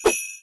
sh_bell_c_2.wav